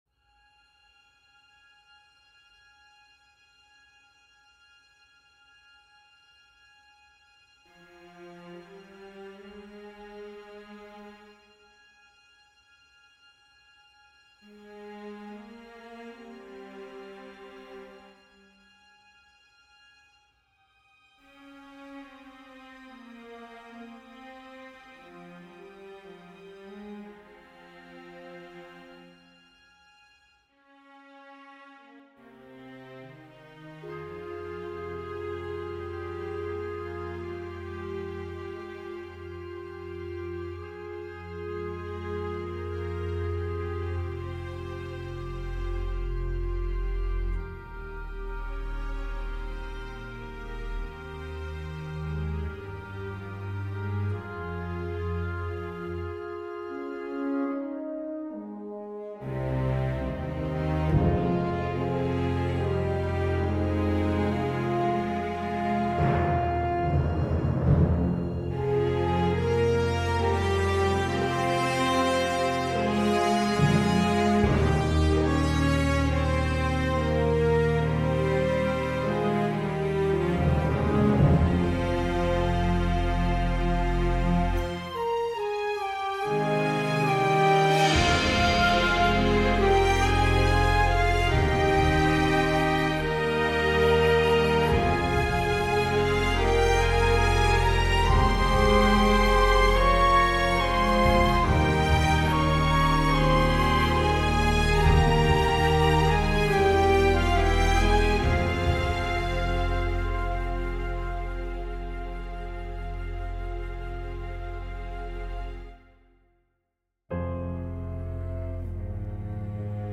It opens strongly - I personally am a big fan of that festival-lydian-mode type sound you have.